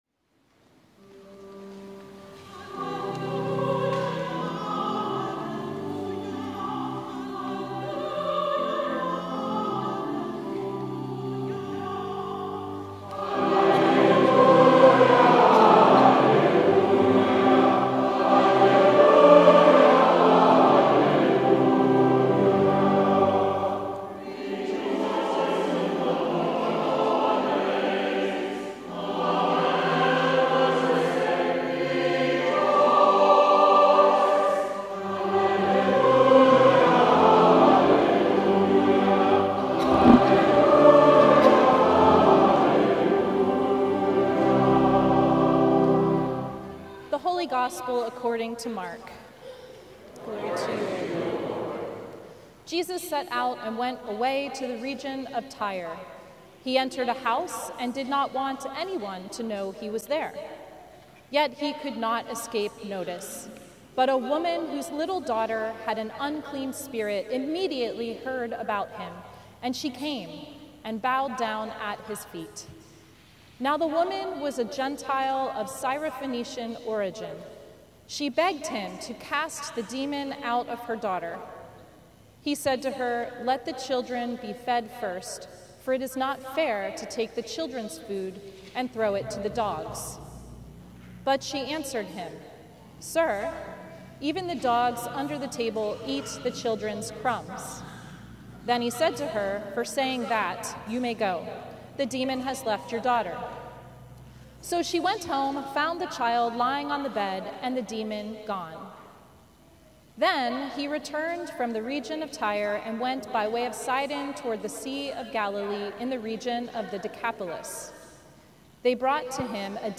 Sermon from the Sixteenth Sunday After Pentecost